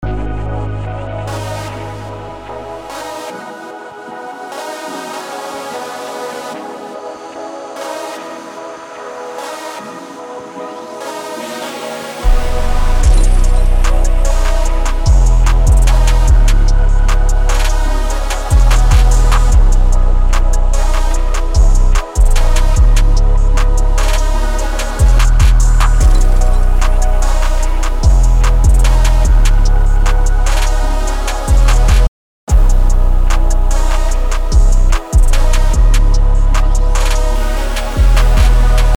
BPM: 148
Key: Ab minor